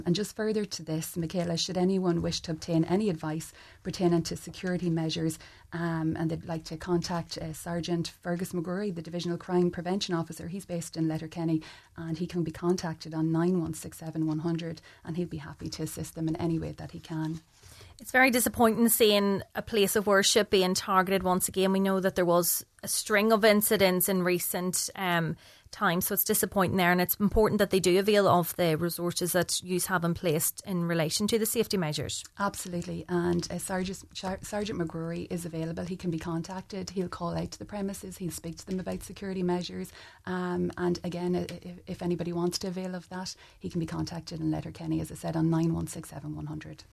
on today’s Nine ‘Til Noon Show